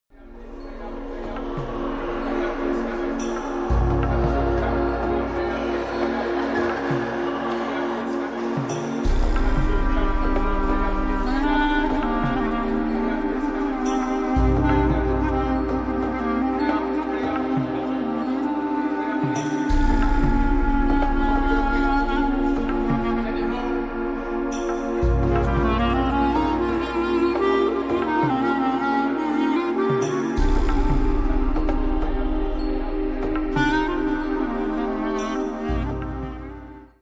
begins with one of his location recordings